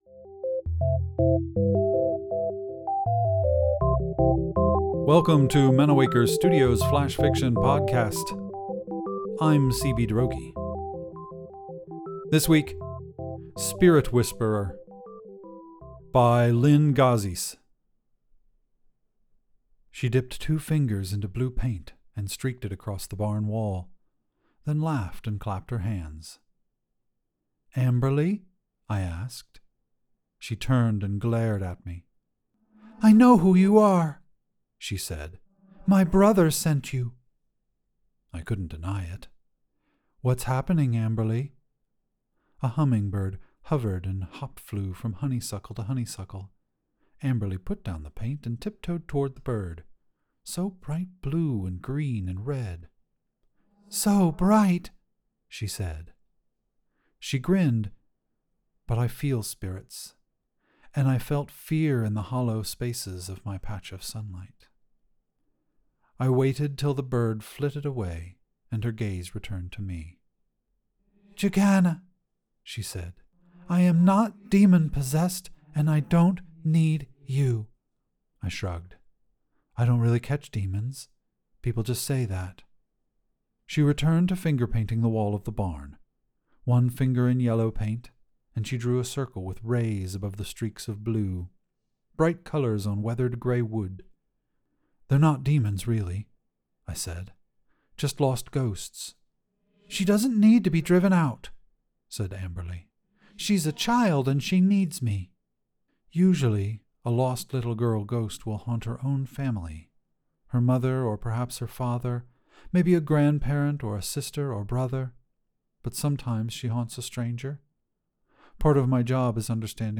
Genres: Drama, Fiction, Science Fiction